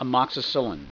Pronunciation
(a moks i SIL in)